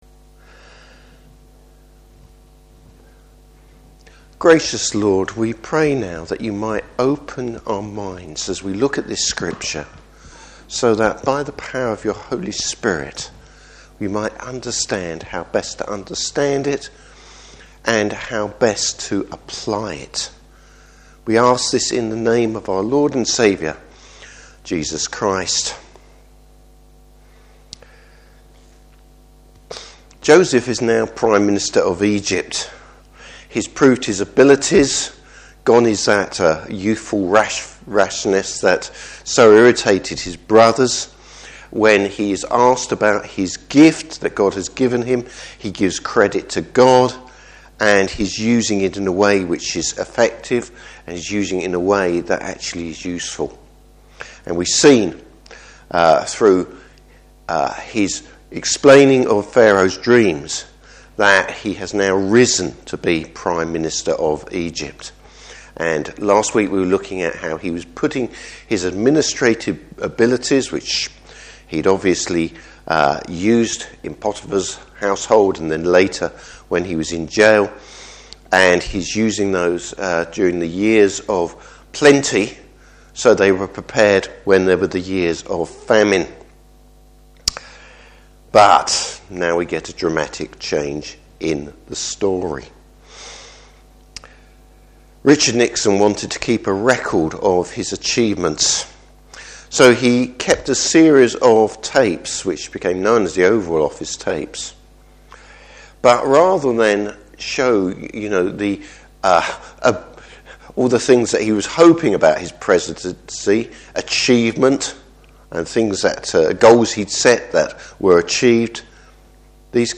Service Type: Evening Service The sin of the past haunts Joseph’s brother’s!